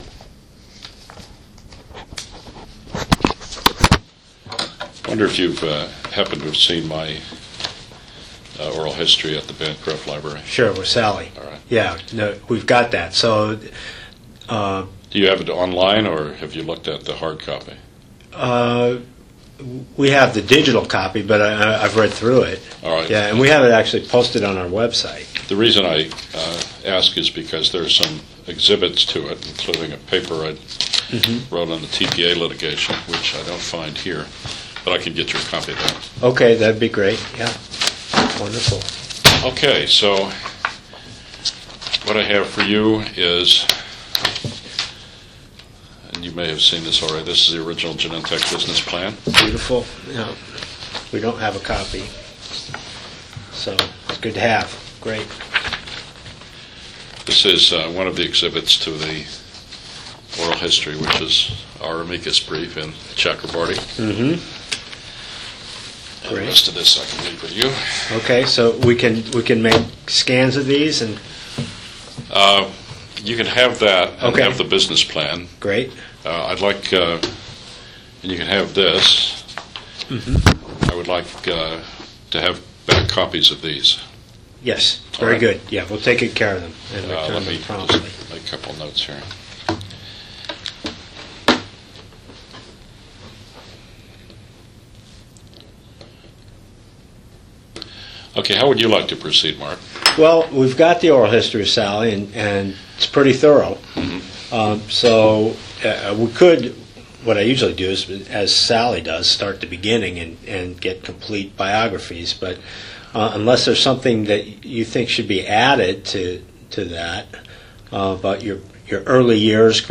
Oral histories Research interview